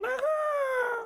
Audio / SFX / Characters / Voices / PigChef / PigChef_02.wav